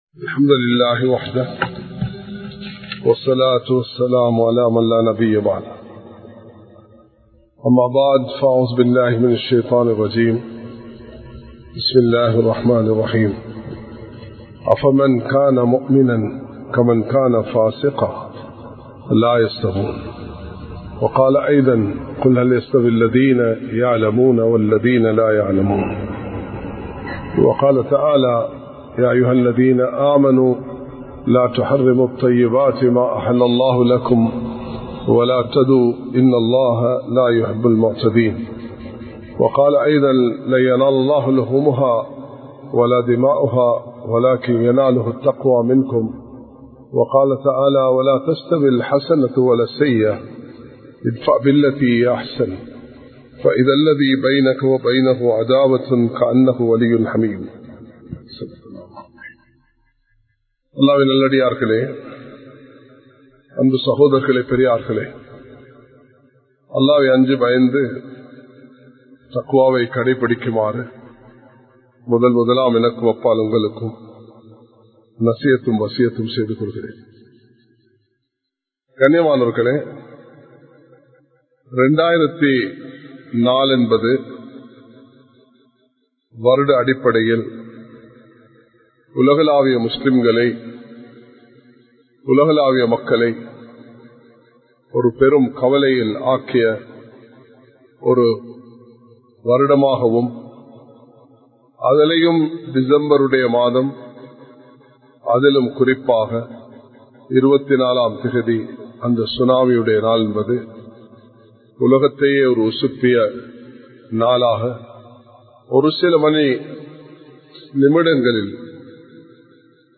பொறுப்புக்களை நிறைவேற்றுங்கள் | Audio Bayans | All Ceylon Muslim Youth Community | Addalaichenai
Colombo 03, Kollupitty Jumua Masjith